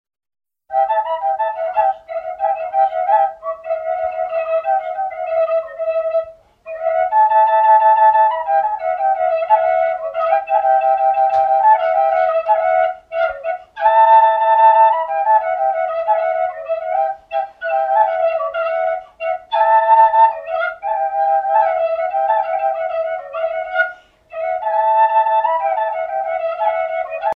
danse : kolo (Serbie)
Pièce musicale éditée